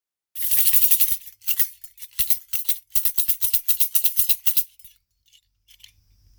【SALE】焼き模様王冠マラカス @1500→1200円
潰した王冠を使ったファンキーな楽器。
素材： 木 王冠 鉄